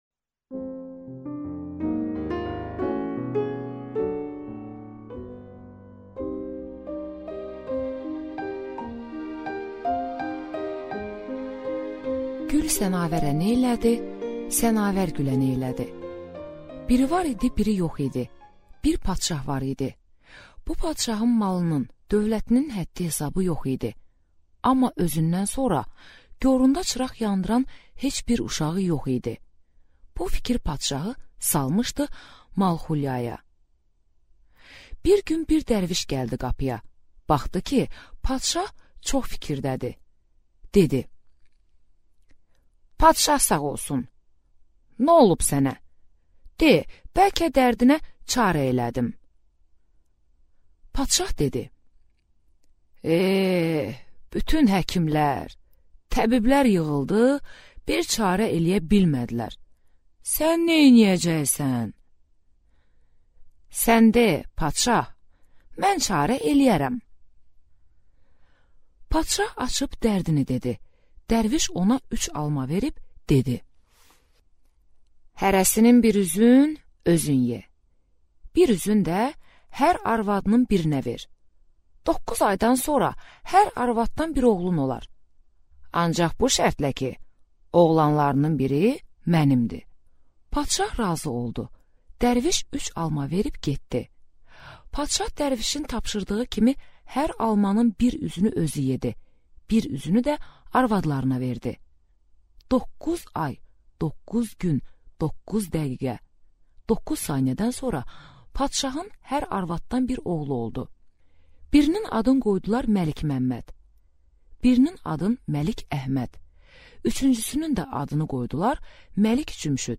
Аудиокнига Gül sənavərə neylədi ?